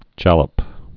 (jăləp, jäləp)